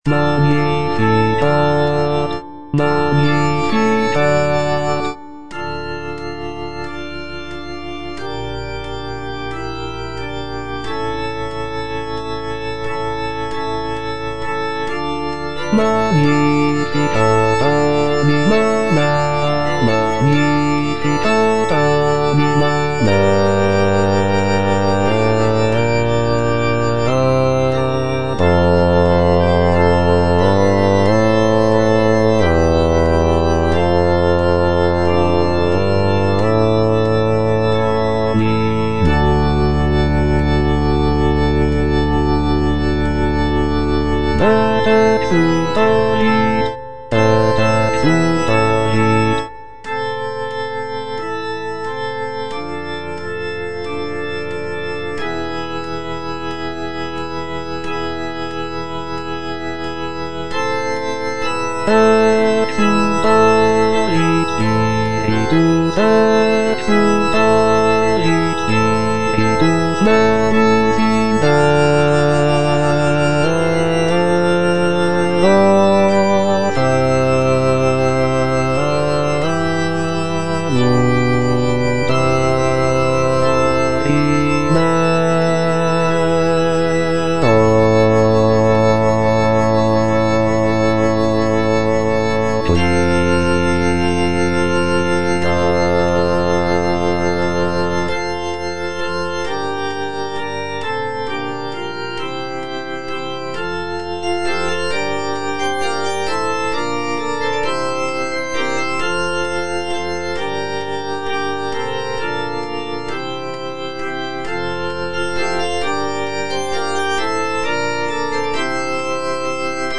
C. MONTEVERDI - MAGNIFICAT PRIMO (EDITION 2) Bass II (Voice with metronome) Ads stop: